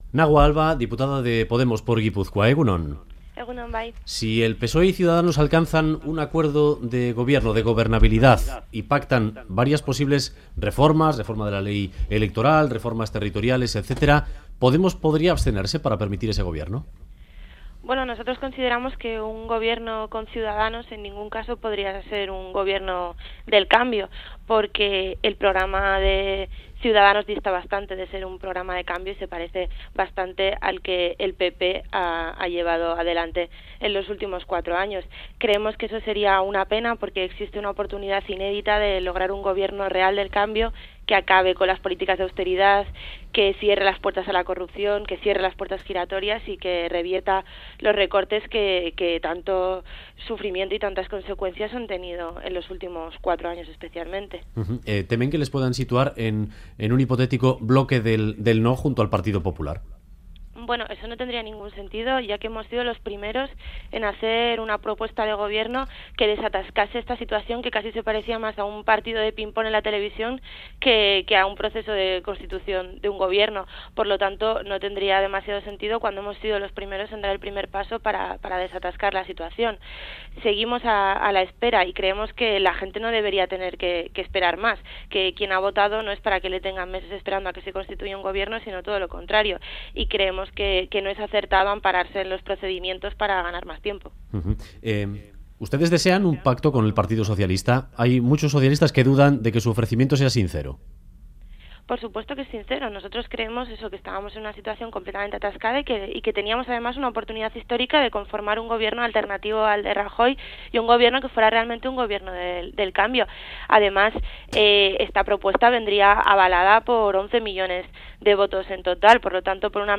Radio Euskadi BOULEVARD 'Un gobierno PSOE-Ciudadanos no puede ser un gobierno de cambio' Última actualización: 02/02/2016 09:22 (UTC+1) En entrevista al Boulevard de Radio Euskadi, la diputada de Podemos por Gipuzkoa, Nagua Alba, ha advertido de que un gobierno del PSOE con Ciudadanos no puede ser un gobierno del cambio, y ha reiterado que la oferta de pacto de Podemos al Partido Socialista es sincera y una oportunidad histórica para un gobierno progresista y de cambio. Además, ha afirmado que Podemos no quiere que se repitan las elecciones porque hay temas sociales que son urgentes, pero que si hay nuevos comicios los afrontarán con tranquilidad.